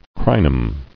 [cri·num]